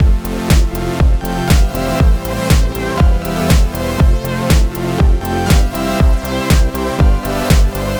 Данный фрагмент не несёт никакой эстетической ценности - просто набор приятных мне звуков с Minilogue. Хаус явно не мой конёк (Бас всё-таки решил брать с ATC-X). Minilogue->RME-UCX->Auria Pro (сайд-чейн + L2 на мастере).